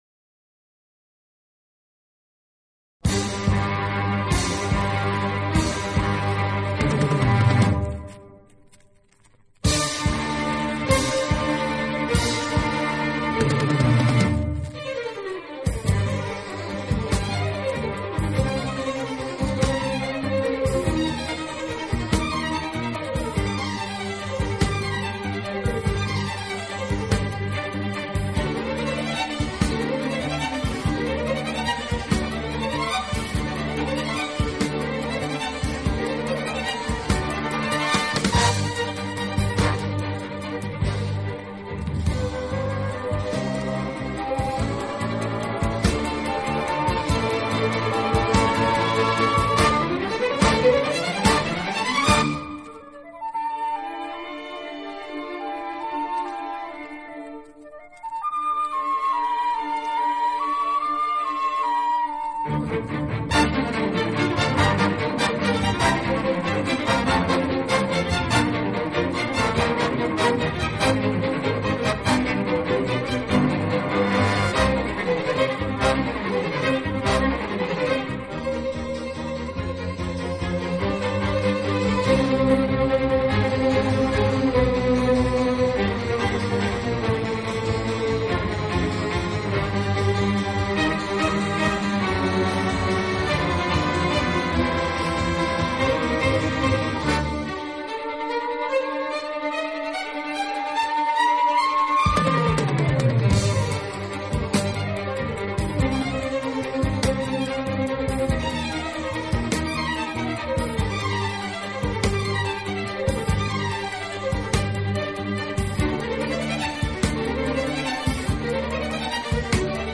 【轻音乐】
体，曲风浪漫、优雅，令人聆听時如感轻风拂面，丝丝柔情触动心扉，充分领略